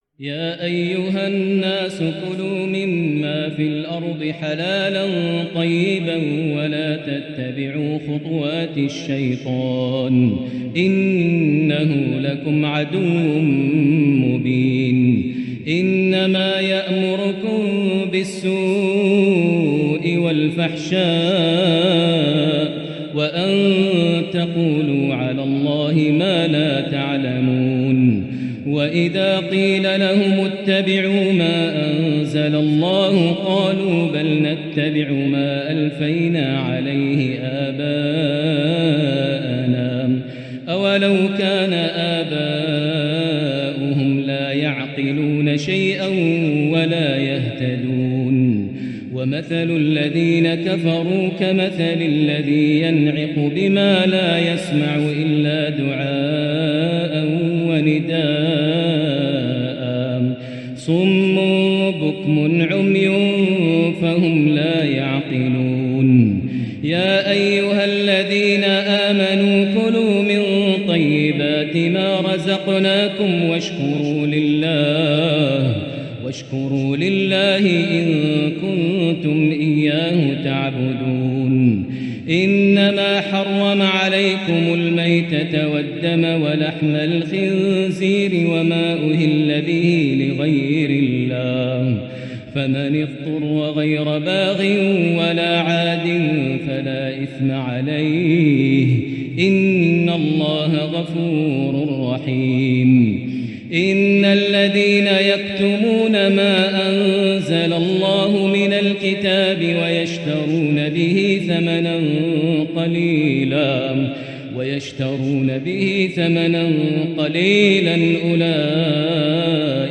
الشيخ المُحبر د. ماهر المعيقلي يتجلى بأداء كُردي مُنقطع النظير من سـور البقرة | ليلة ٢ رمضان ١٤٤٤هـ > مقتطفات من روائع التلاوات > مزامير الفرقان > المزيد - تلاوات الحرمين